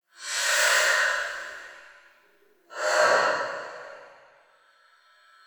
Breathe.wav